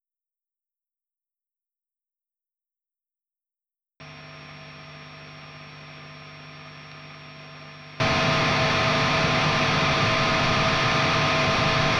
ну и запись emg 81 в инструментальный вход lexicon io, на канале повешан mercurial ss-11x нойз гейт отключен, первые три сек - овер на всю, гейн 0, потом овер 0 гейн на всю, и овер и гейн на всю в принципе макс значения то не юзабельны, всегда можно найти компромисс между шумом и мощью, включить нойзгейт.